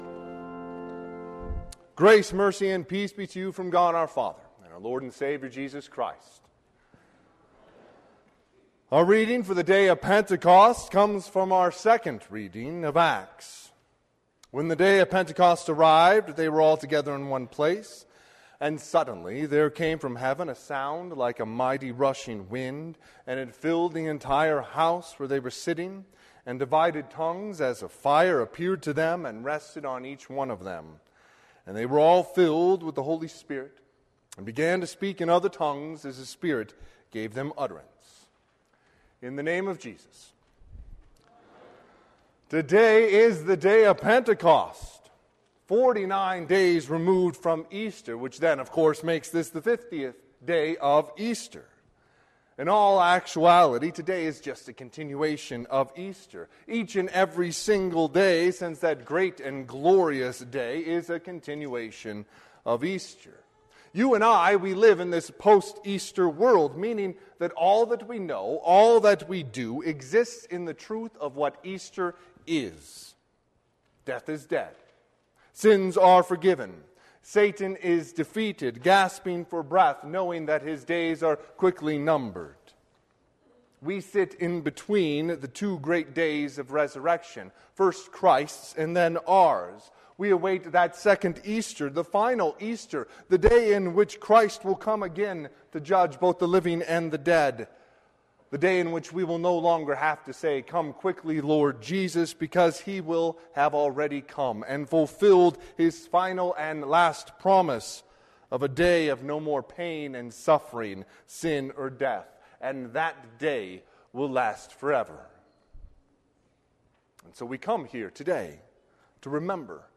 Sermon - 6/5/2022 - Wheat Ridge Lutheran Church, Wheat Ridge, Colorado